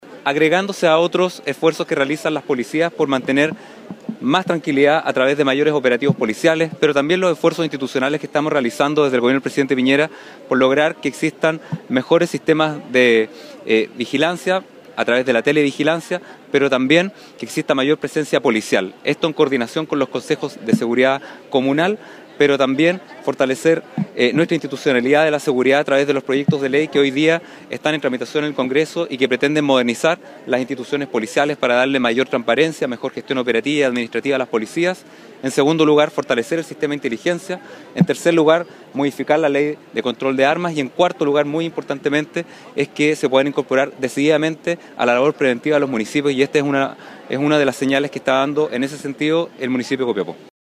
La mañana de este martes se llevó a cabo la ceremonia de inauguración del proyecto Patrullaje Preventivo “MAS SEGURIDAD”.
Patricio Urquieta, Intendente de Atacama, destacó el trabajo en conjunto entre las entidades y la adquisición de recursos para tener mejores sistemas de vigilancia y presencia policial: